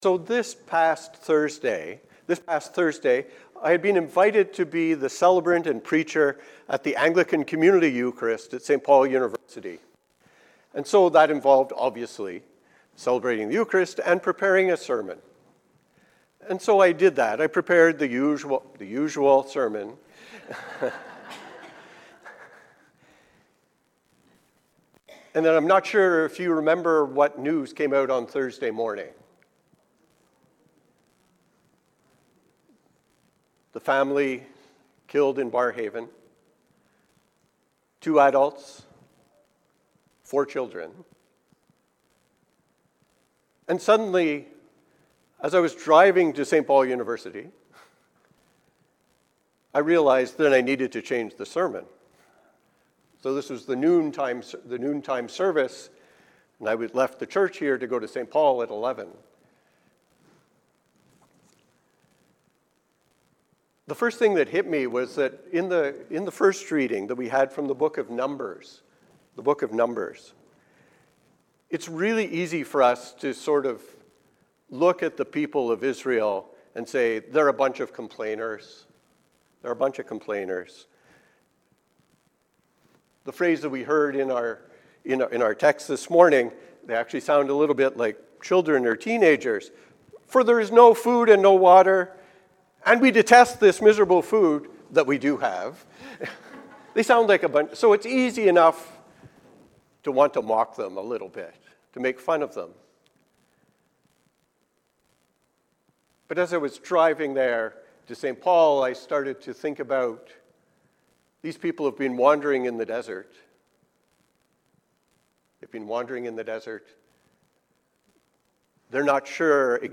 Look to the light. A Sermon for the 4th Sunday in Lent